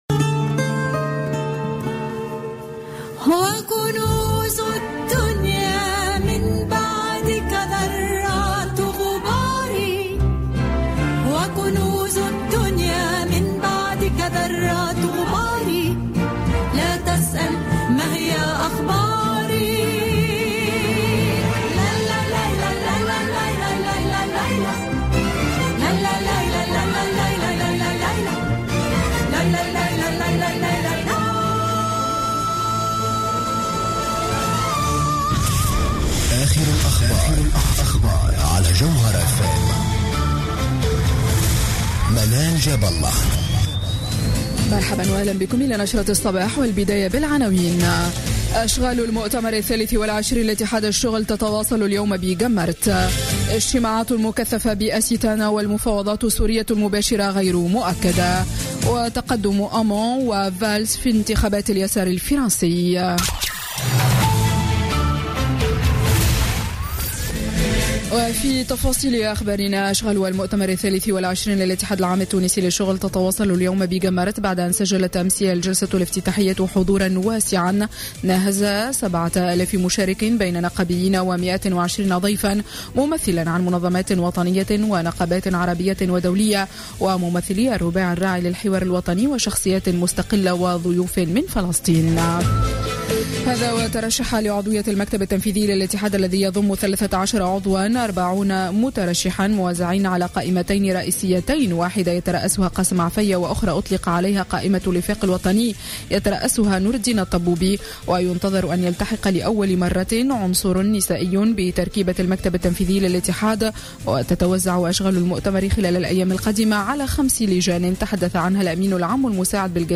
ولاحظت في افتتاحيتها لـ "الجوهرة اف ام" أن المدرسة غير قادرة على استقطاب الجيل الجديد من التلاميذ واستيعابهم خاصة وأنها مازالت تتعامل مع هذا الجيل بنفس الطرق البيداغوجية والمضامين المعرفية المعتمدة خلال العقود الماضية.